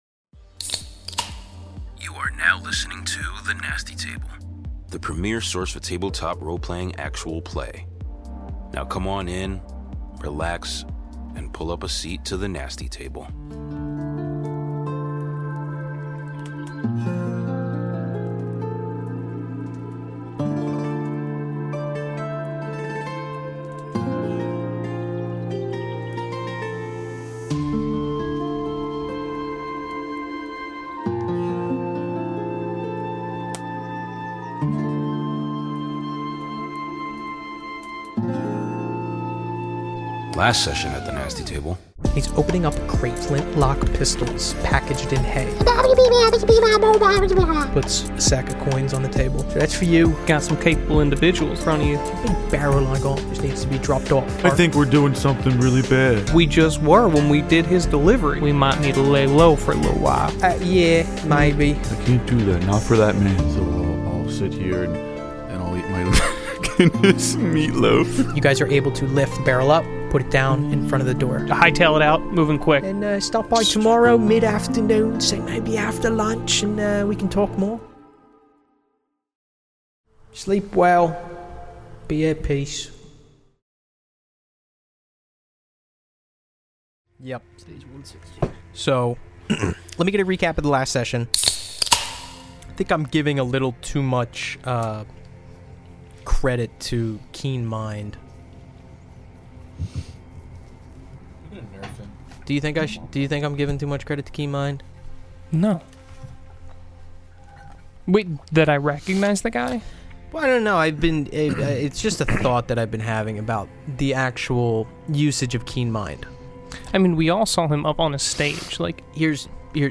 Join The Nasty Table, where five friends and longtime players of Table Top Role-Playing games play together in various campaigns across different systems and settings (Delta Green, Dungeons & Dragons, Call of Cthulhu, StarFinder and more).
Tune in for new TTRPG actual play episodes every Tuesday!